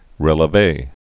(rĕlə-vā)